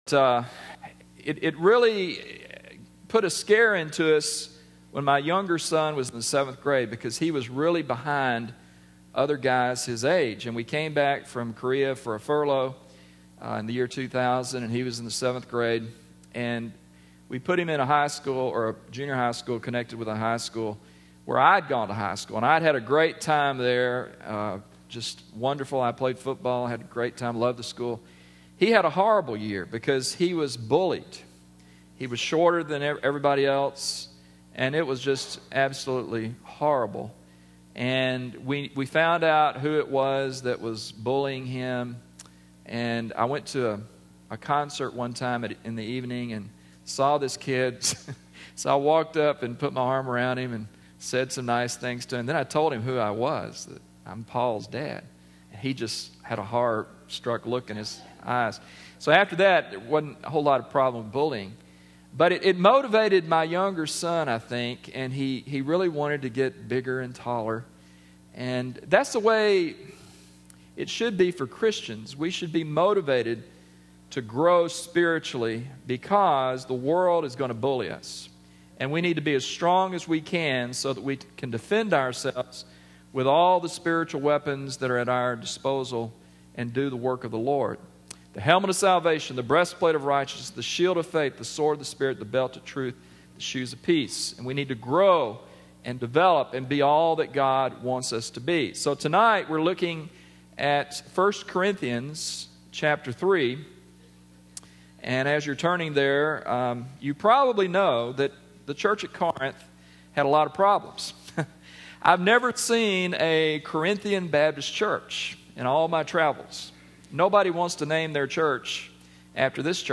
Guest sermon